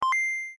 coin.ogg